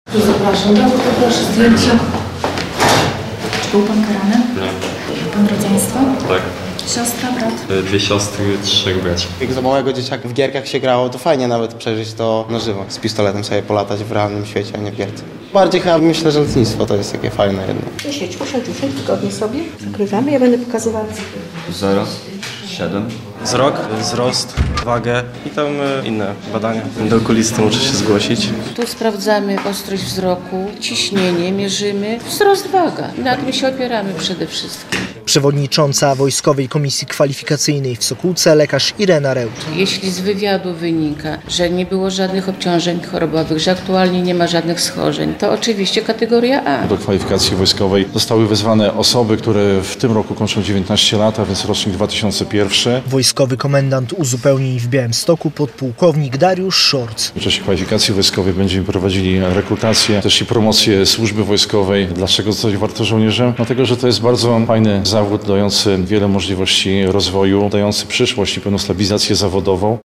Kwalifikacja wojskowa w Sokółce - relacja